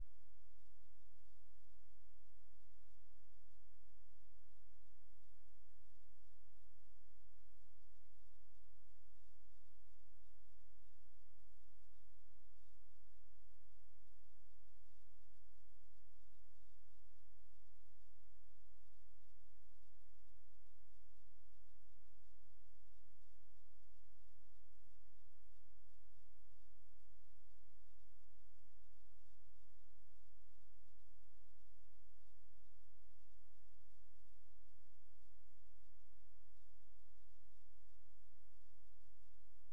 有关AIC3106的底噪问题
新软件+LDO作电源.aac
3、后对电路进行优化，用LDO电源芯片替代原来的DCDC供电（3.3V），同样在不插入任何音频设备的情况下，所录制出来的文件有很大的改善，但还有类似于白噪声的底噪；
4、对AIC3106的内部相关寄存器进行重新配置，最终得到如附件中的音频文件，戴耳机，把电脑音量调到最大，也会有底噪。